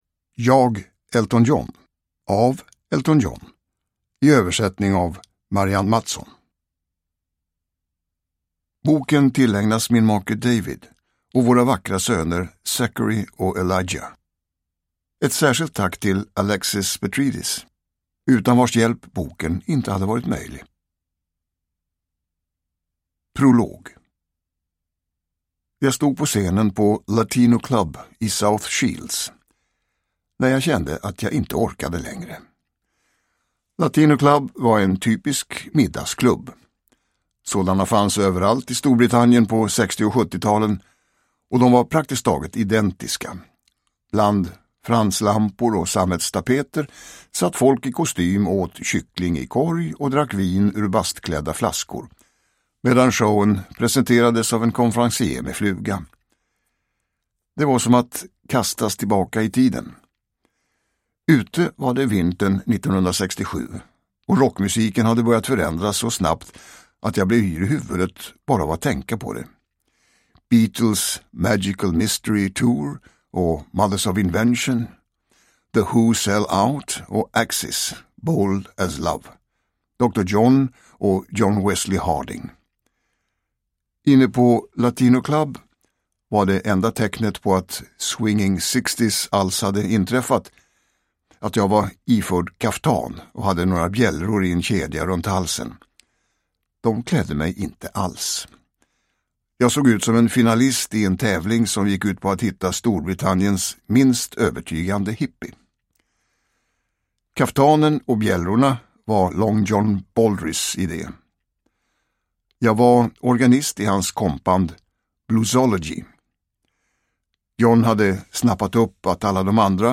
Jag – Ljudbok
Nedladdningsbar ljudbok
Uppläsare: Tomas Bolme